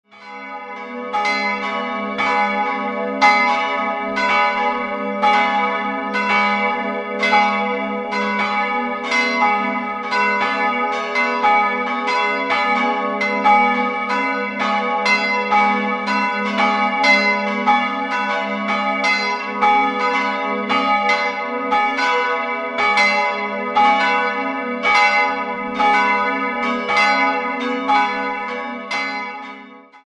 3-stimmiges TeDeum-Geläute: gis'-h'-cis''